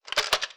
SFX_Remove_02.wav